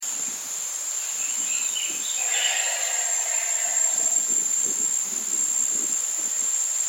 Cathartes burrovianus urubitinga - Cuervo cabeza amarilla
Cuervo cabeza amarilla - Cathartes burrovianus.wav